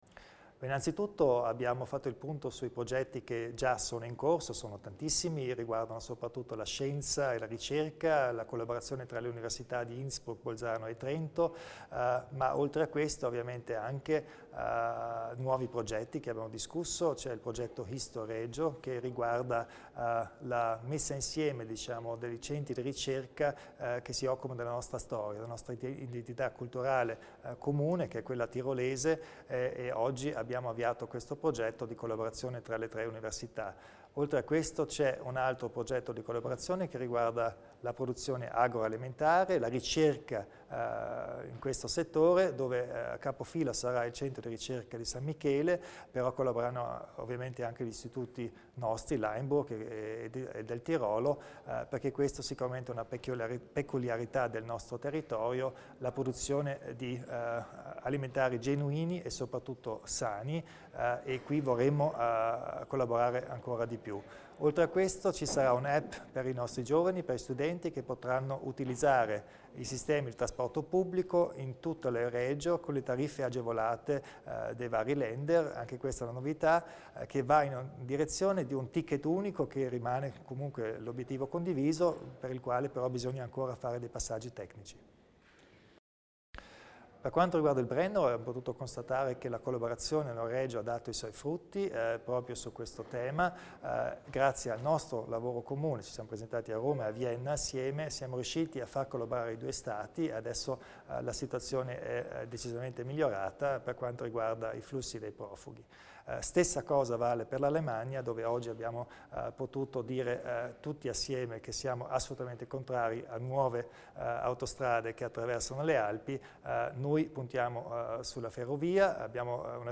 INTV_ARNO_KOMPATSCHER_it_(1+2).mp3